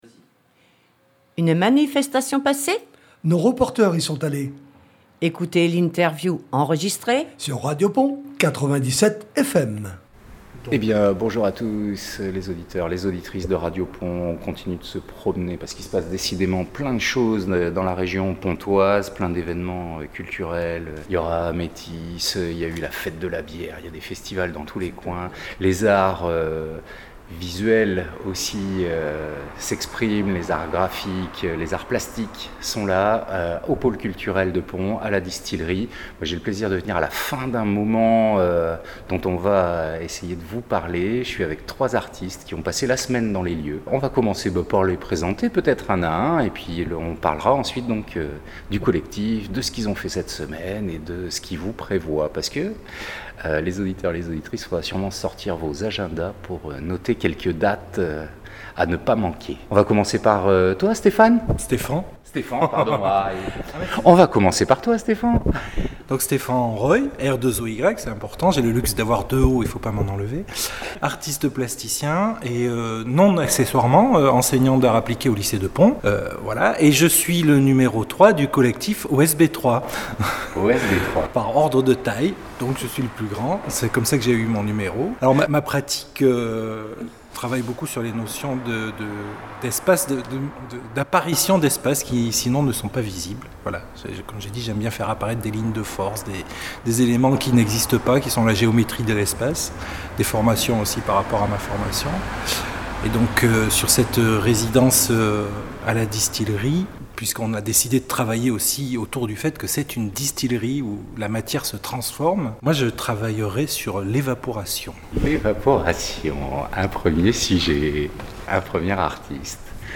Ecouter ici l'interview OSB3 par RadioPons / RadioPons 97 Fm OSB3 installe ses 3 univers plasticiens imbriqués à La Distillerie, Pons